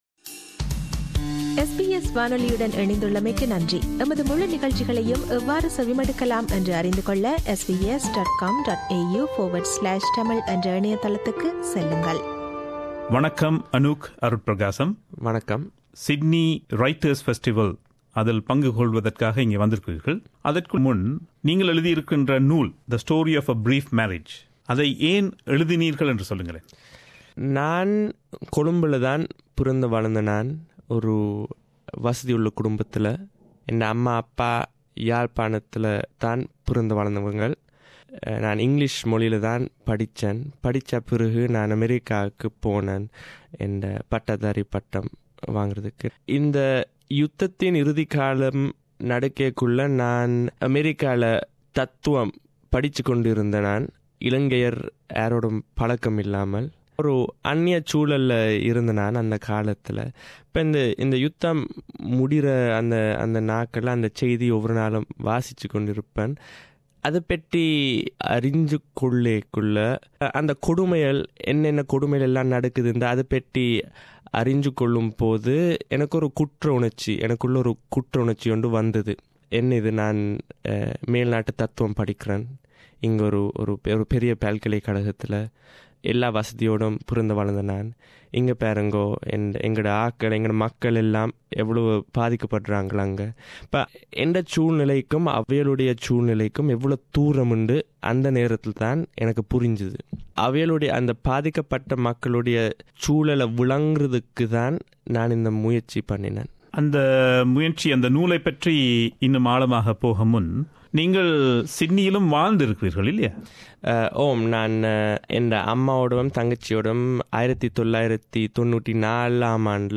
நேர்காணலின் முதல் பாகம் இது.